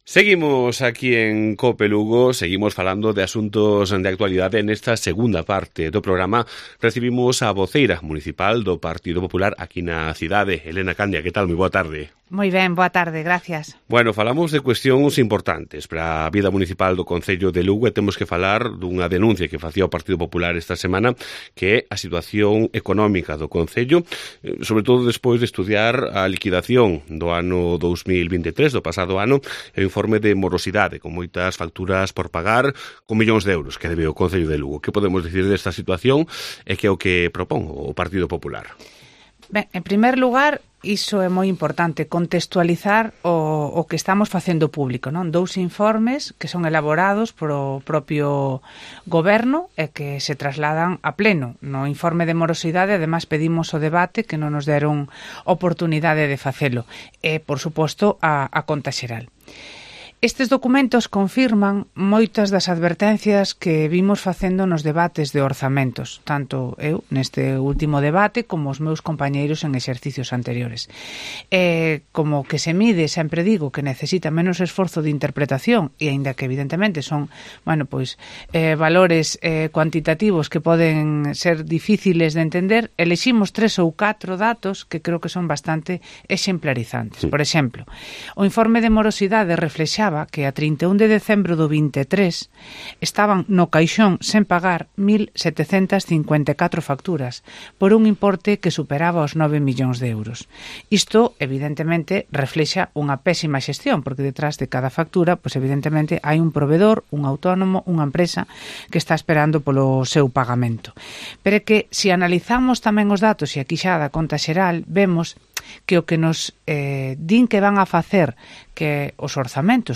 Entrevista a Elena Candia en COPE Lugo (07/03/2024)